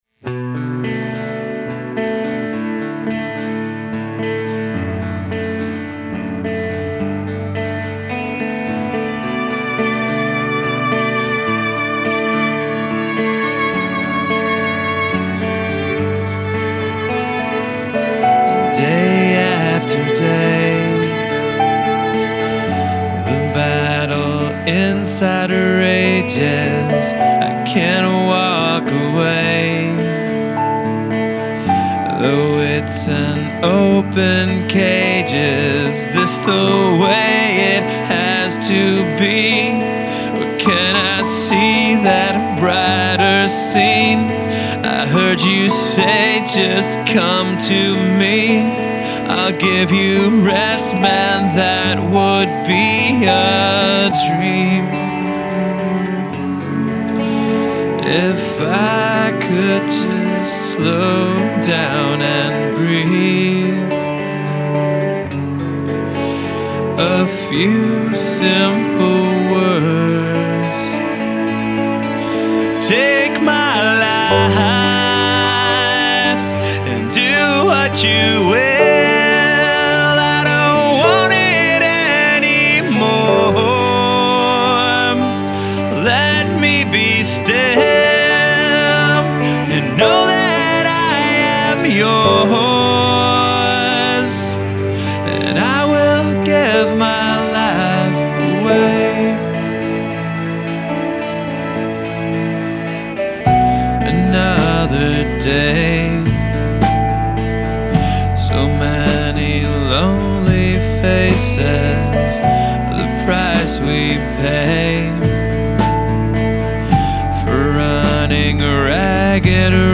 Acoustic guitars, vocals, some electric guitars
Drums, bass, rhythm and lead guitars, keys